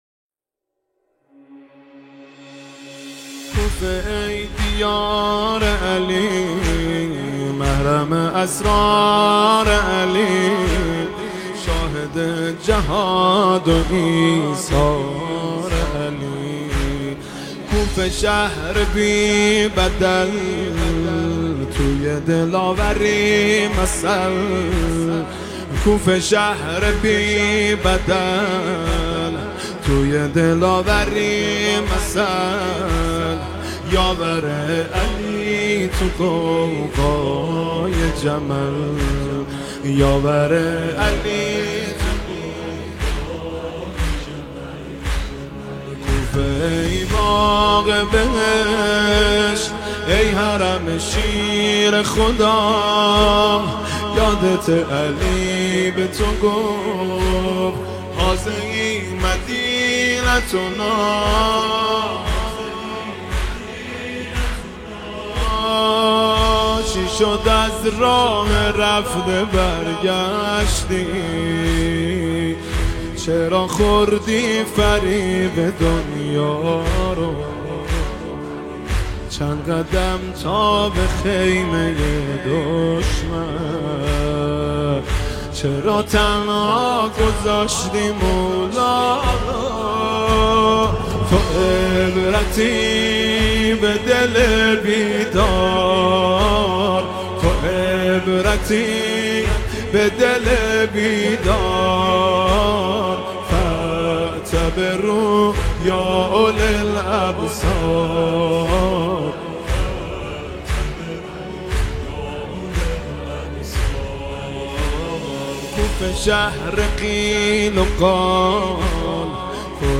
با نوای حزین و دلنشین
مداحی